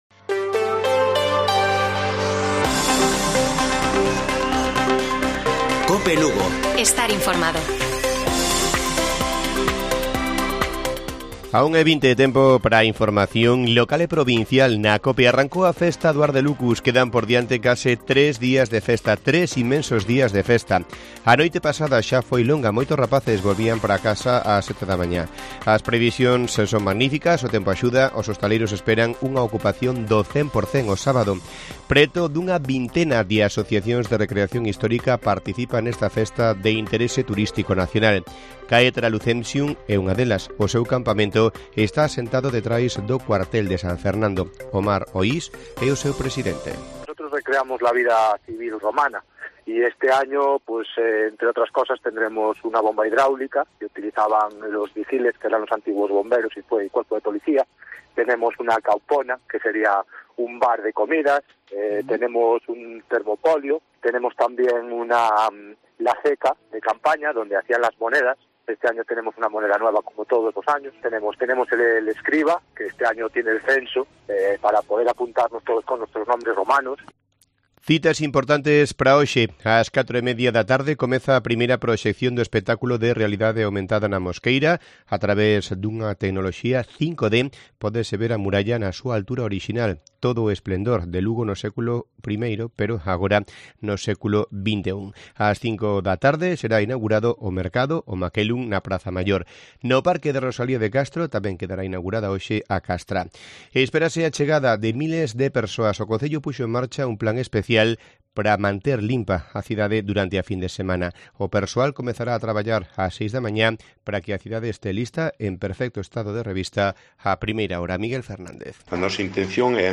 Informativo Mediodía de Cope Lugo. 10 DE JUNIO. 13:50 horas